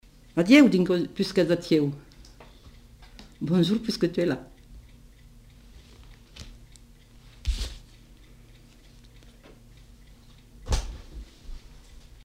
Expression populaire
Lieu : Cathervielle
Genre : forme brève
Type de voix : voix de femme
Production du son : récité
Classification : locution populaire